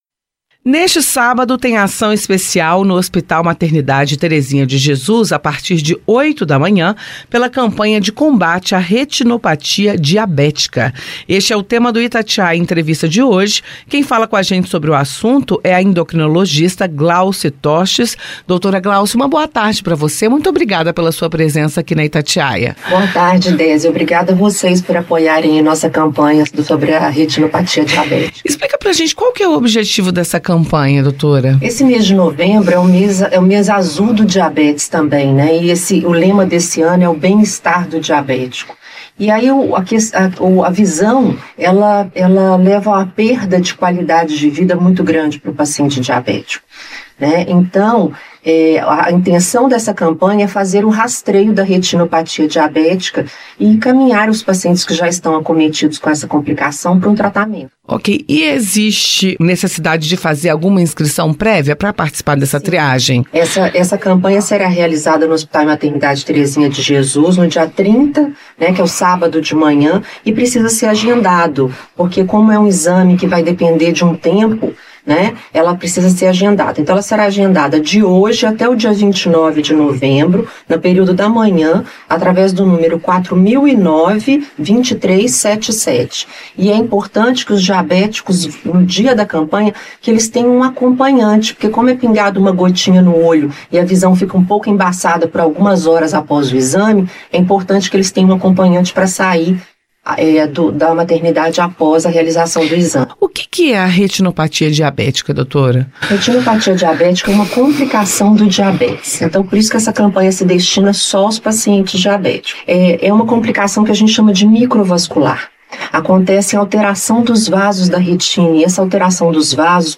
Itatiaia Entrevista